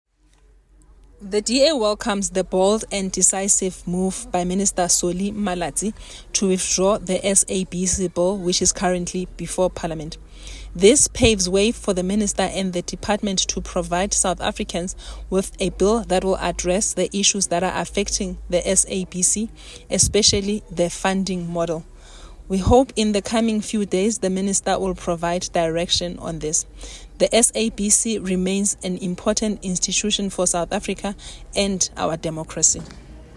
Sesotho soundbite by Thsolofelo Bodlani MP.